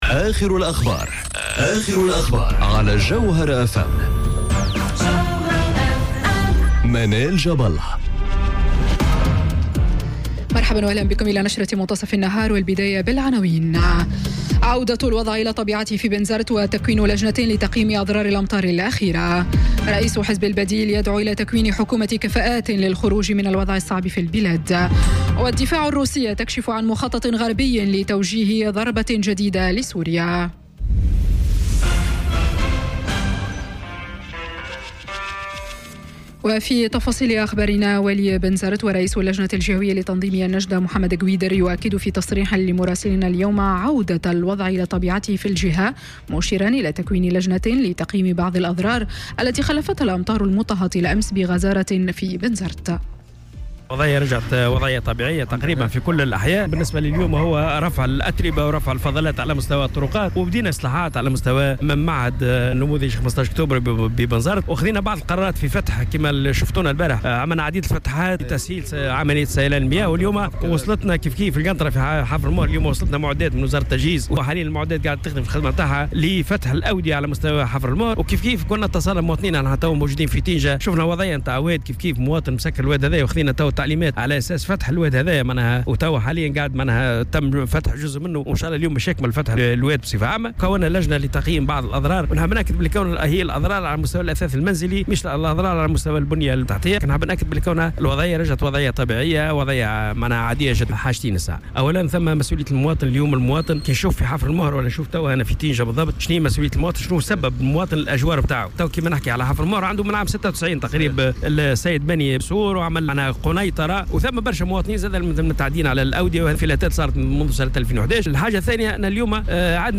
نشرة أخبار منتصف النهار ليوم السبت 25 أوت 2018